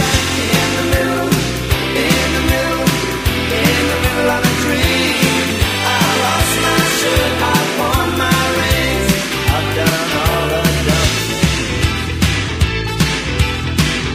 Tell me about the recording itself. This is a sound sample from a commercial recording. from the vinyl album Reduced quality: Yes